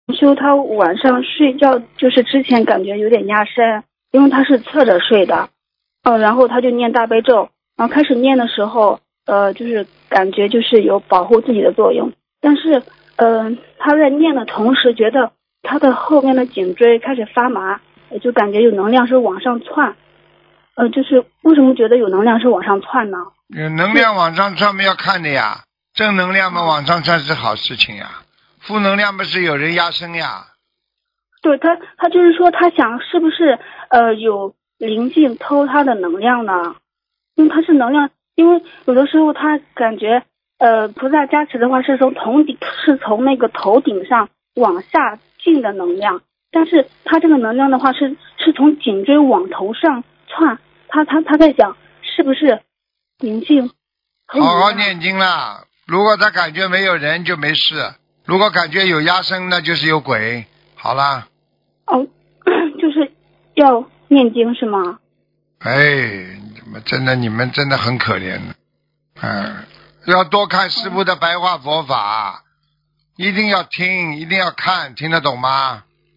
目录：☞ 2019年08月_剪辑电台节目录音_集锦